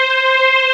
P A D06 01-L.wav